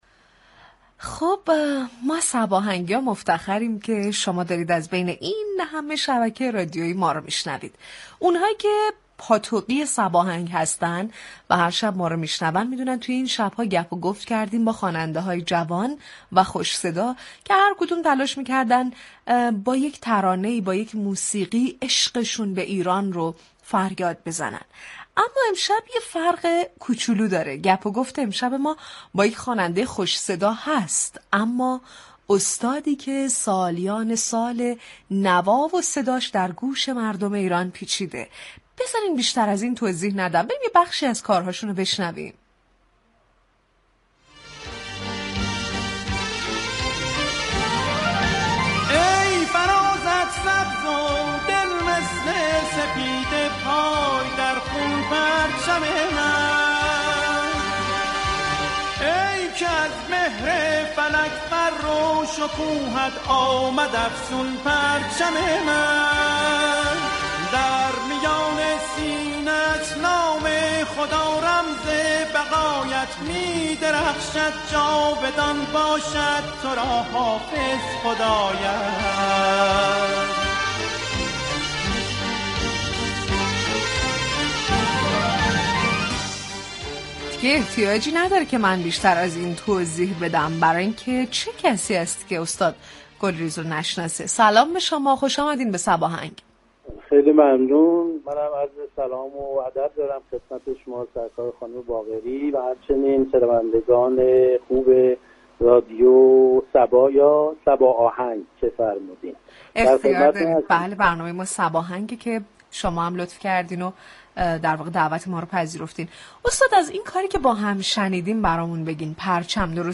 رادیو صبا در برنامه موسیقی محور «صباهنگ» میزبان محمد گلریز خواننده خوب كشورمان شد
گلریز در دامه با اجرای زنده سرود وطنم آرزوی موفقیت و سربلندی برای ایران و ایرانیان كرد و حسن ختام گفتگو این شعر مولانا را تقدیم شنوندگان كرد ای خدا این وصل را هجران مكن سرخوشان عشق را نالان مكن